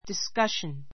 diskʌ́ʃən